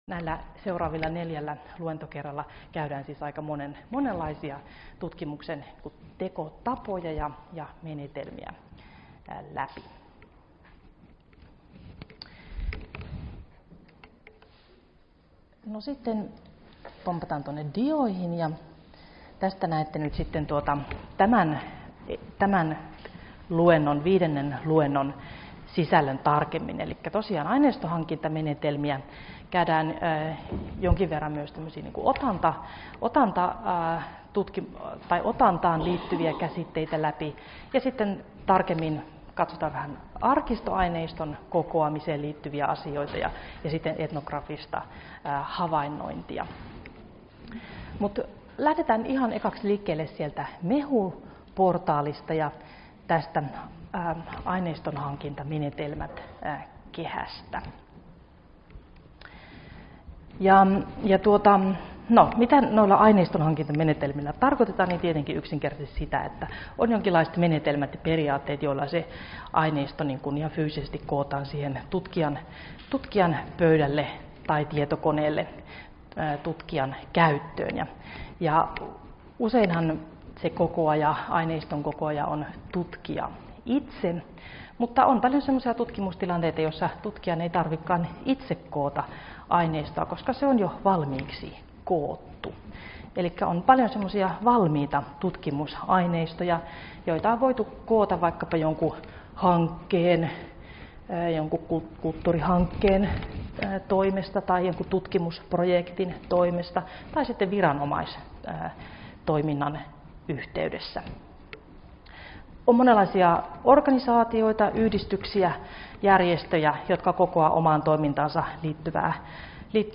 Luento 5 - Aineistonhankintamenetelmiä 1 — Moniviestin